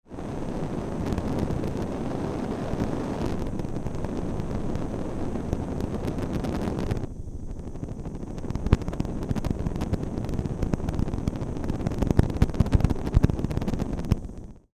Шум пламени газовой горелки